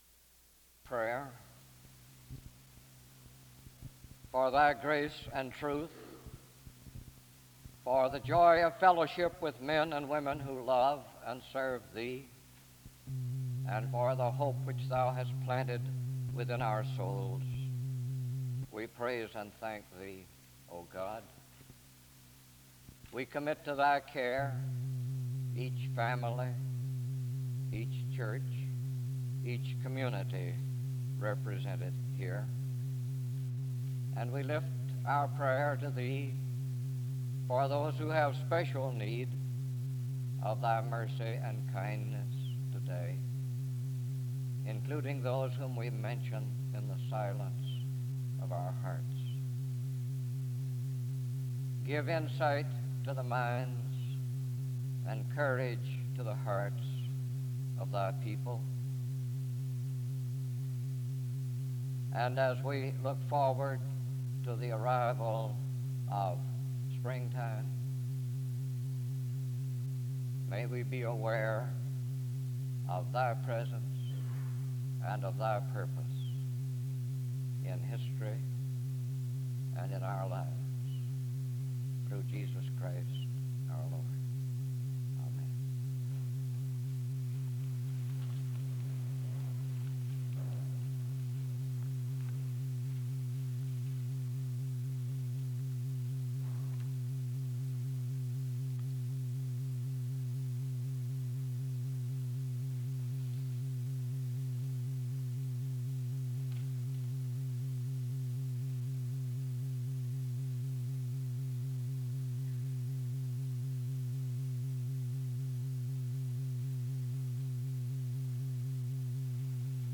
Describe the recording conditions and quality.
The service begins with a prayer (0:00-1:25).